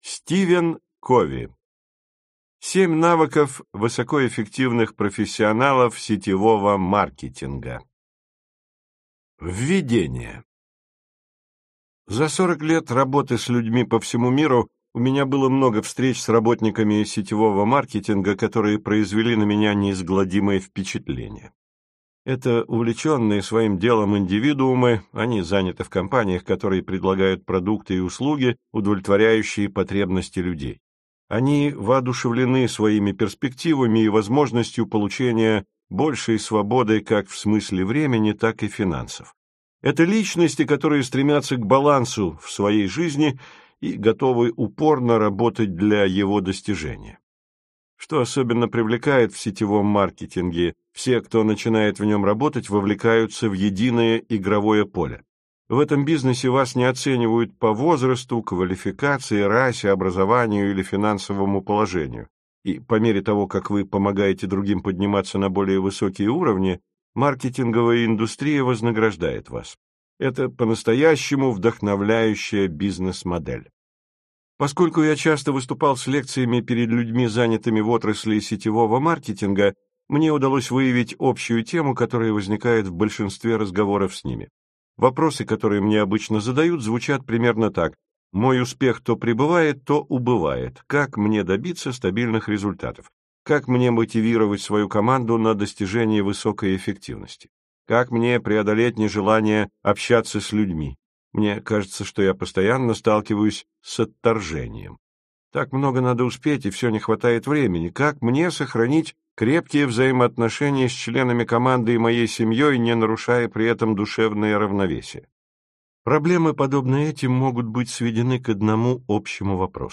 Аудиокнига 7 навыков высокоэффективных профессионалов сетевого маркетинга | Библиотека аудиокниг